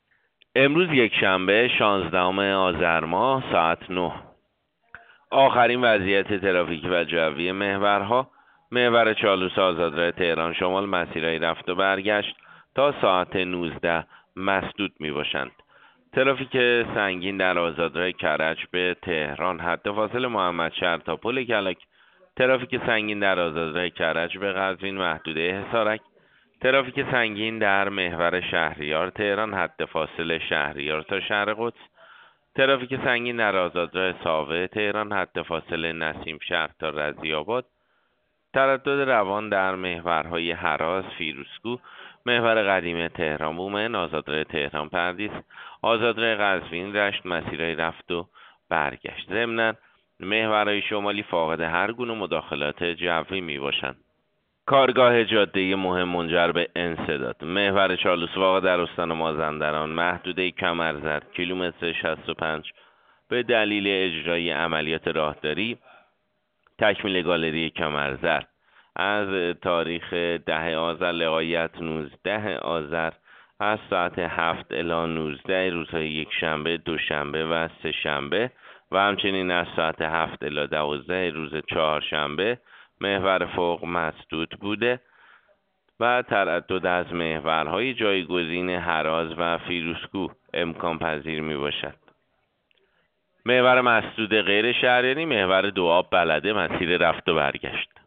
گزارش رادیو اینترنتی از آخرین وضعیت ترافیکی جاده‌ها ساعت ۹ شانزدهم آذر؛